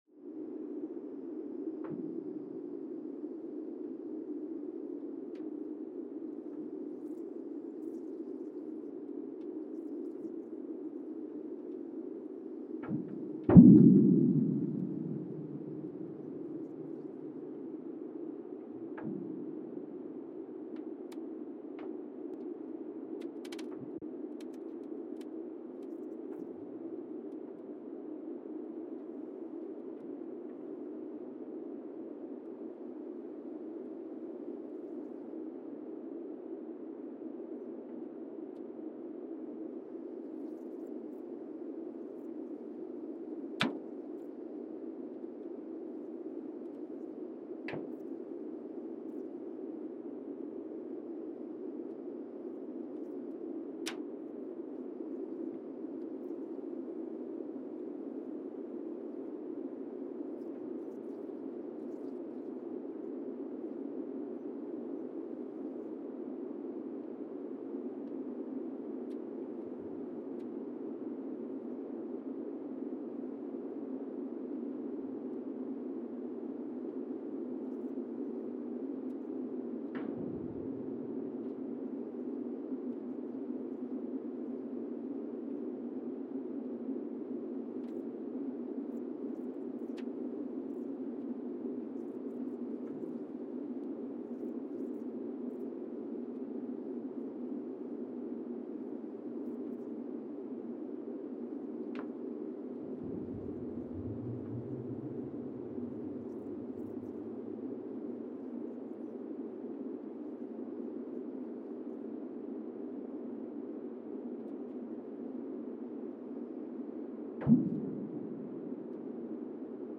Monasavu, Fiji (seismic) archived on May 29, 2023
Sensor : Teledyne Geotech KS-54000 borehole 3 component system
Recorder : Quanterra Q330HR @ 20 Hz
Speedup : ×1,800 (transposed up about 11 octaves)
Loop duration (audio) : 05:36 (stereo)
SoX post-processing : highpass -2 90 highpass -2 90